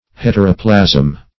Search Result for " heteroplasm" : The Collaborative International Dictionary of English v.0.48: Heteroplasm \Het"er*o*plasm\, n. [Hetero- + Gr.